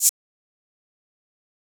Closed Hats
Waka HiHat - 1 (3).wav